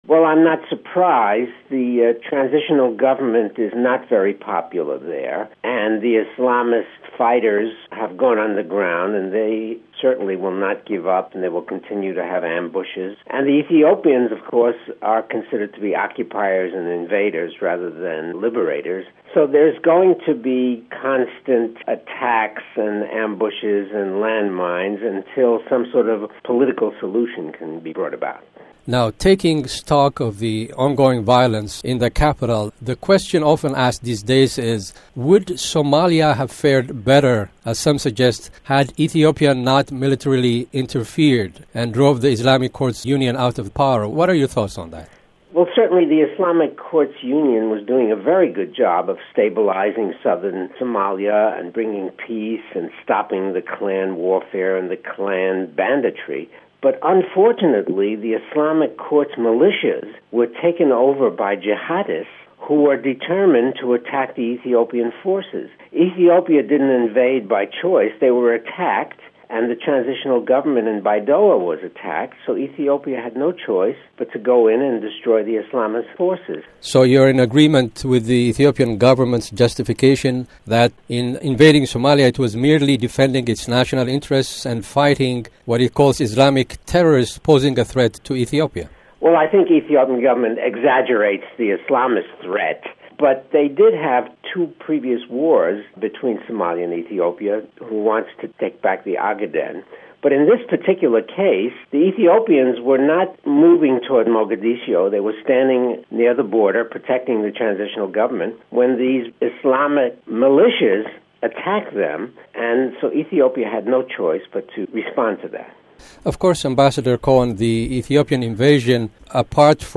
interview with Amb. Herman Cohen (mp3)